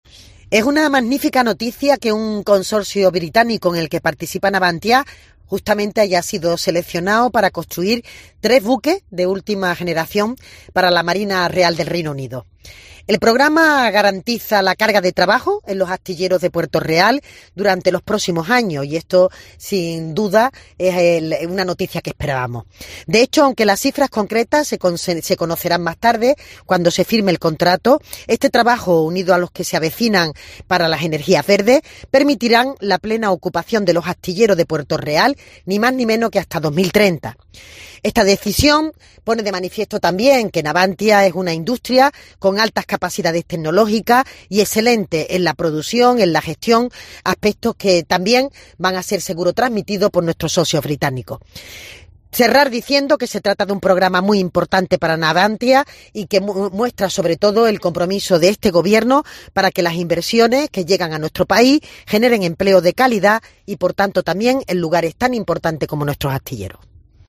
María Jesús Montero, ministra de Hacienda sobre Navantia Puerto Real